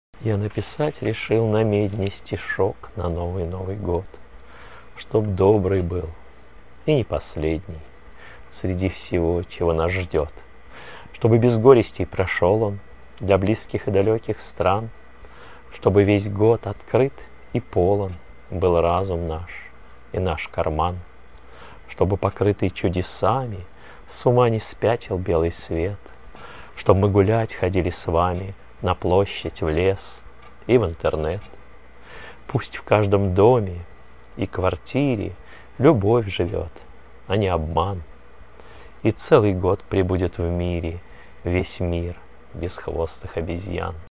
Если не хочется читать, кликните ссылку внизу, и я прочитаю.